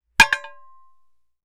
Metal_29.wav